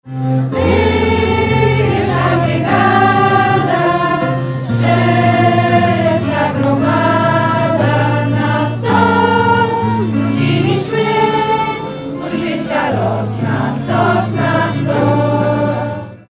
Narodowe Święto Niepodległości w Bielsku-Białej.
W samo południe, przed Ratuszem w Bielsku-Białej, bielszczanie wspólnie odśpiewali Mazurka Dąbrowskiego. Pomagał w tym chór "Echo".